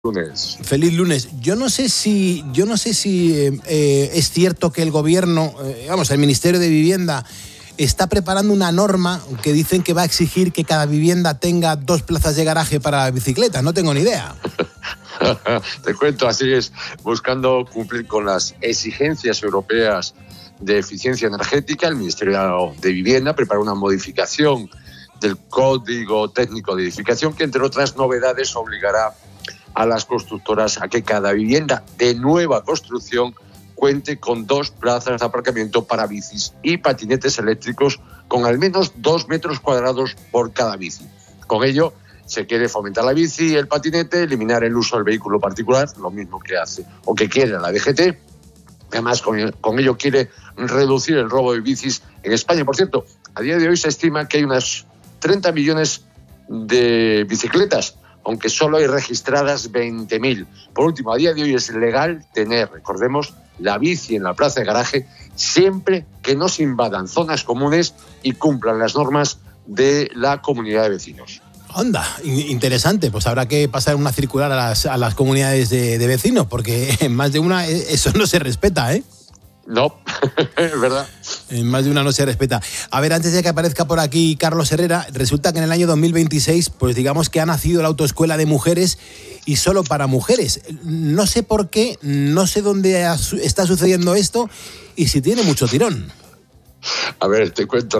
La información ha sido comentada en el programa 'Poniendo las Calles' de COPE